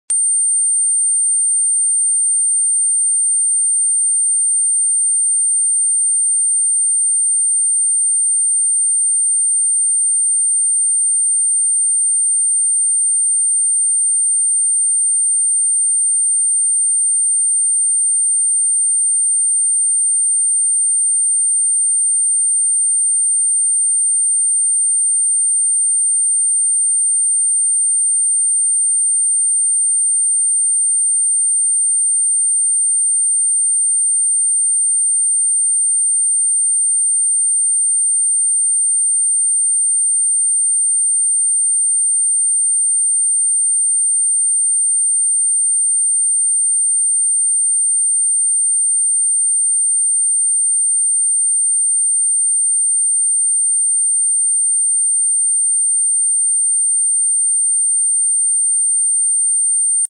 the Mp3 Sound Effect Tune into 8888 Hz, the frequency of abundance.